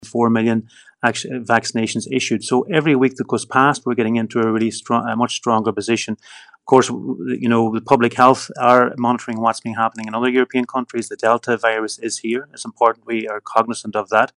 Minister Charlie McConalogue says that doesn’t mean the crucial tourist season will be over for restaurants: